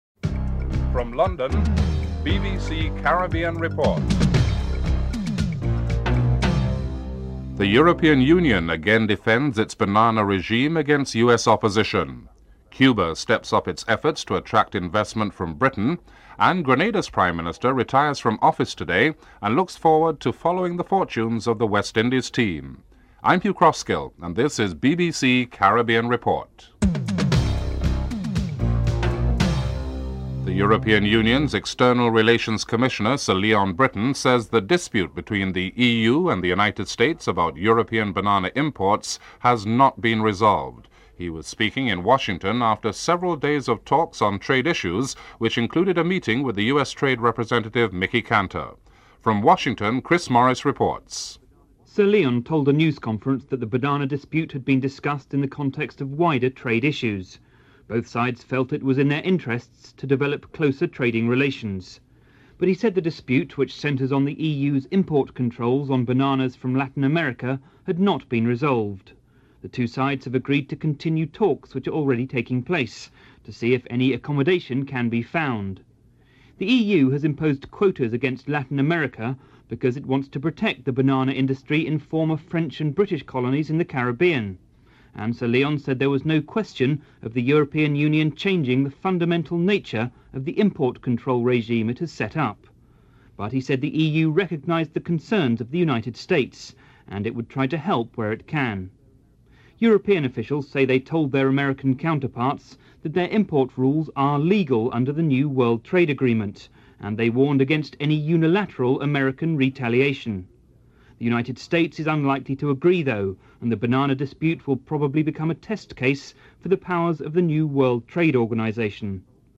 4. Interview with British businessmen about the possibility of them investing in Cuba (04:24-05:53)
5. Interview with Jose Luis Rodriguez on Cuba's self-imposed structural adjustment programme and the issue of NAFTA (05:54-08:24)